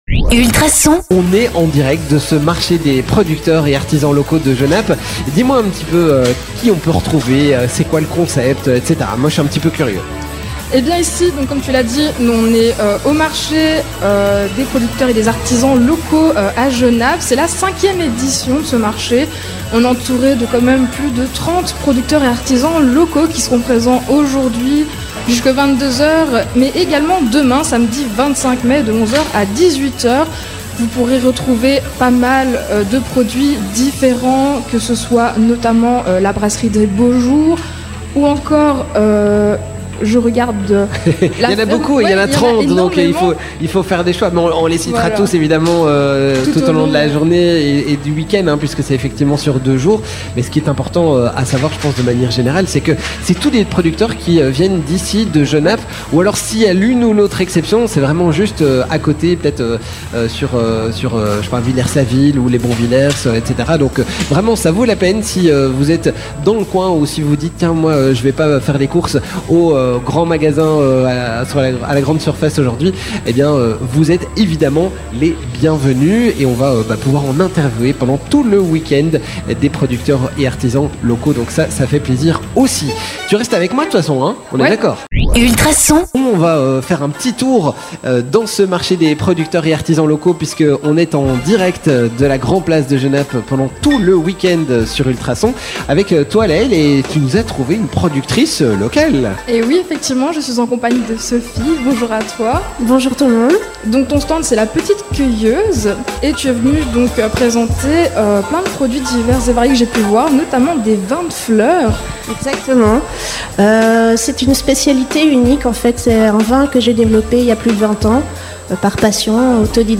Gérard Couronné, Bourgmestre de Genappe (MR)
Benoit Huts, échevin du commerce de Genappe (Les Engagé.e.s)